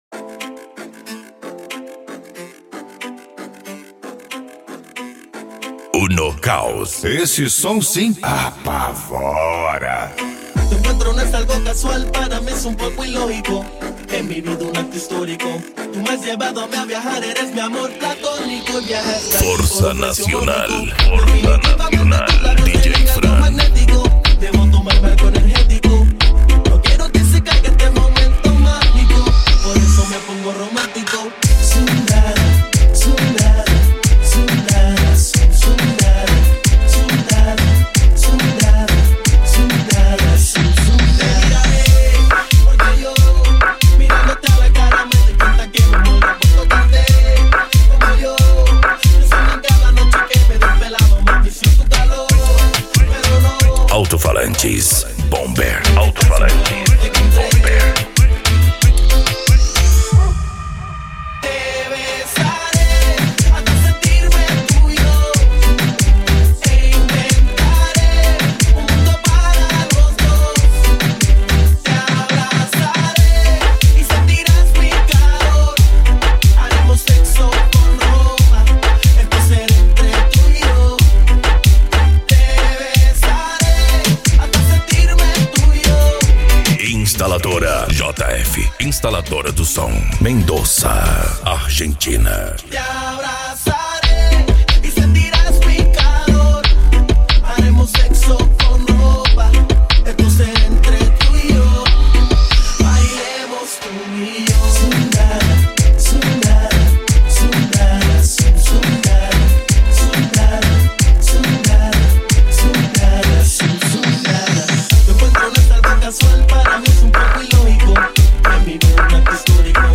Bass
Musica Electronica
Remix